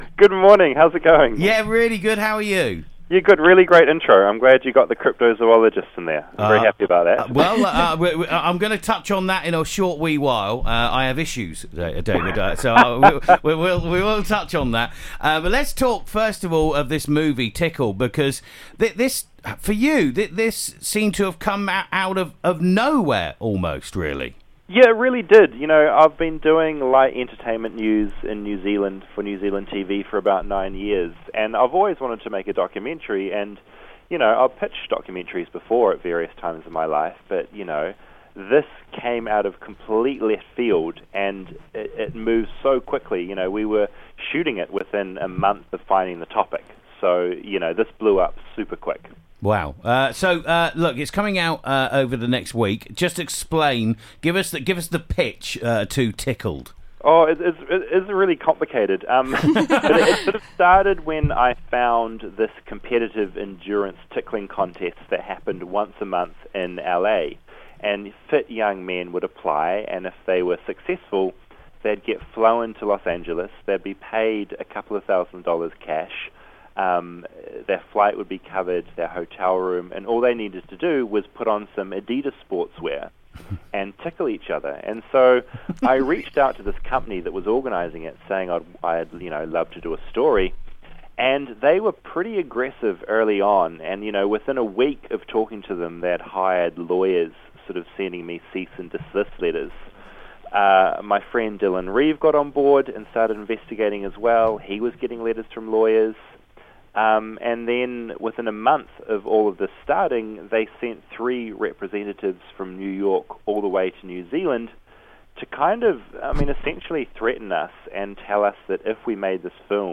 David Farrier talks 'Tickled' on Radio Yorkshire